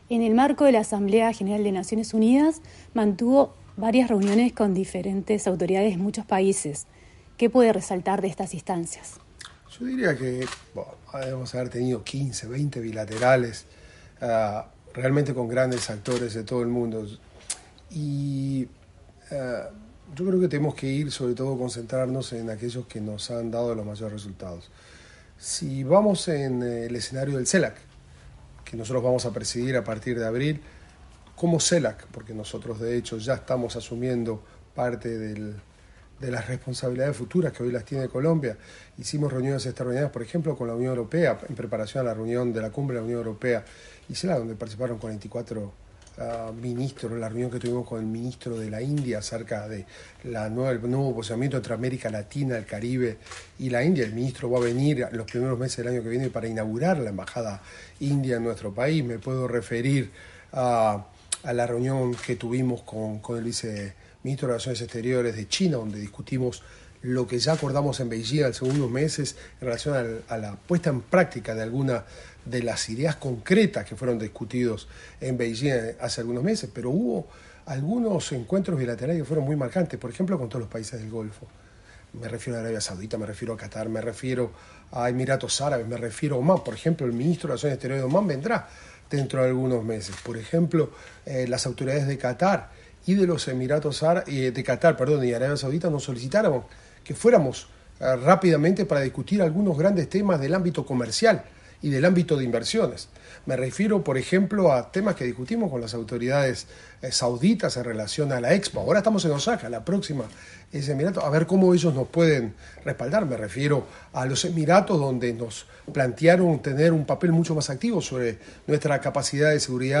Declaraciones del ministro de Relaciones Exteriores, Mario Lubetkin